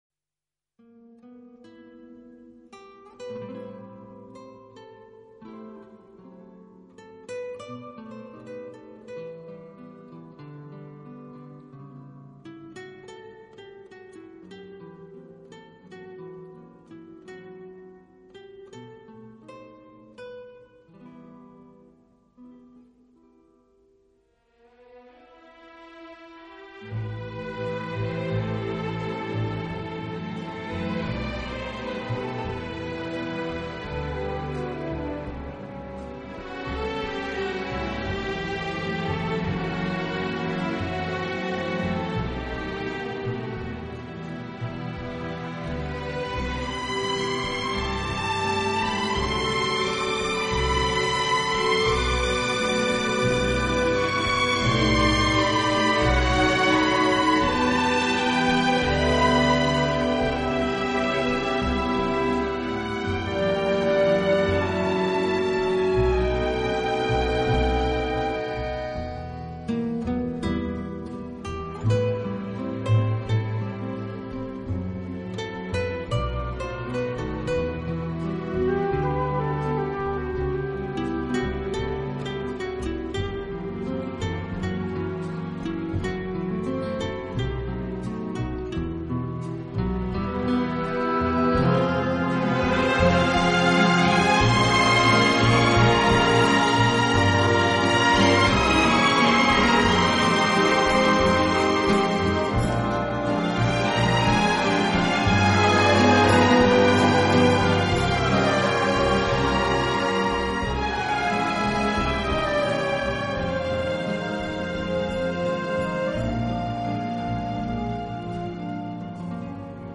这个乐团的演奏风格流畅舒展，
旋律优美、动听，音响华丽丰满。